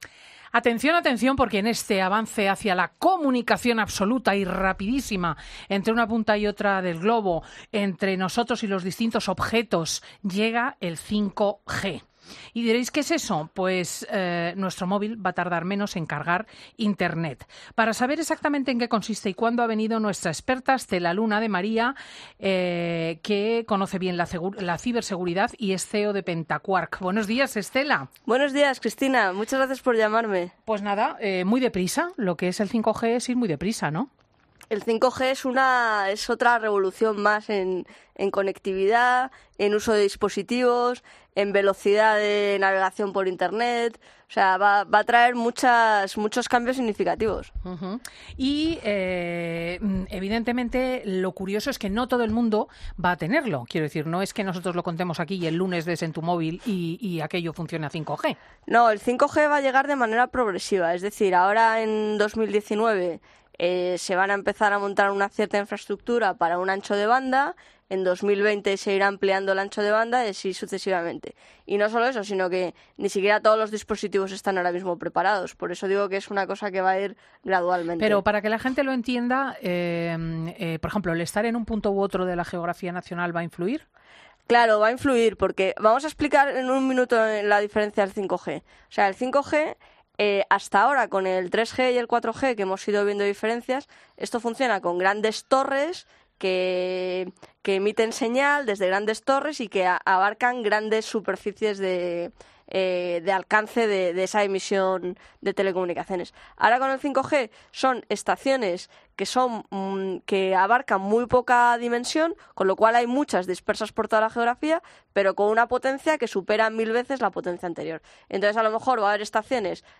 la experta en ciberseguridad